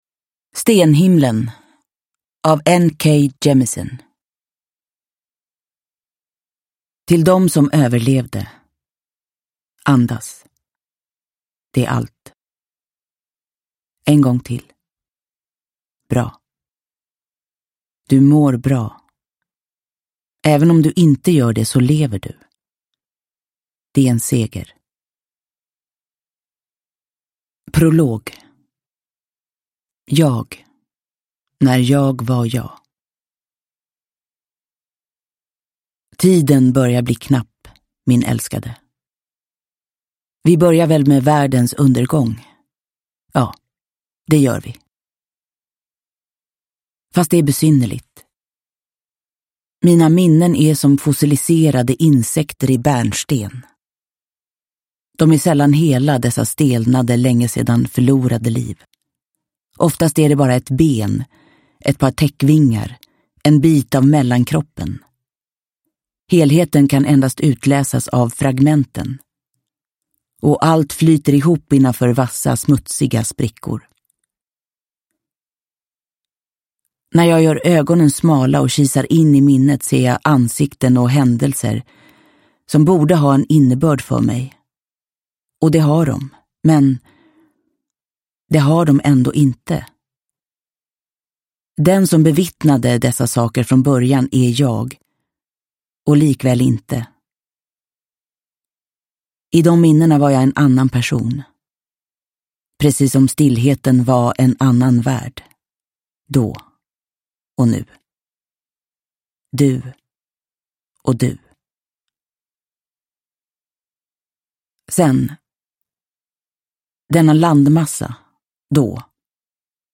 Stenhimlen – Ljudbok – Laddas ner
Uppläsare